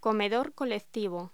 Locución: Comedor colectivo
voz